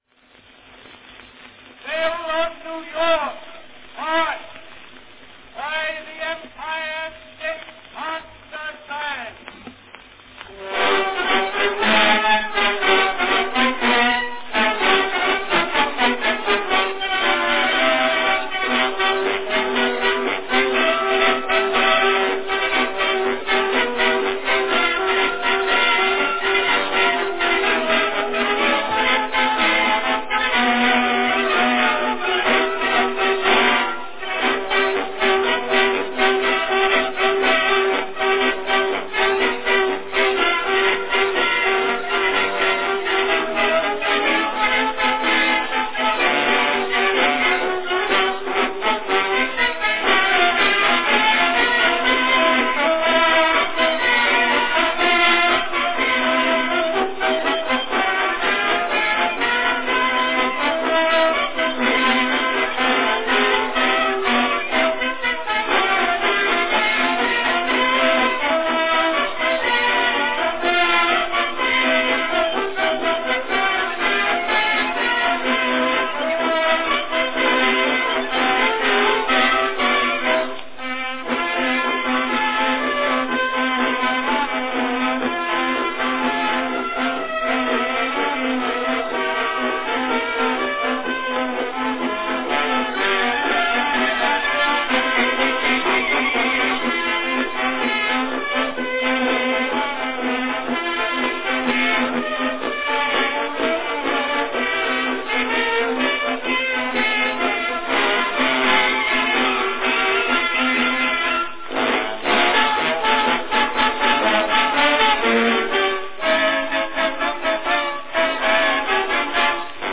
Category Band
Performed by Empire State Concert Band
Announcement "Belle of New York March, by the Empire State Concert Band."
This cylinder carries the characteristic somewhat brash sound of a record duplicated pantographically from a master cylinder.
Notice how the band seems to have been signaled at around 2:25 (and maybe again at 2:38) to up the tempo in order to finish the piece before the end of the cylinder – they barely made it!